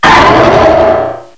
cry_not_mega_lucario.aif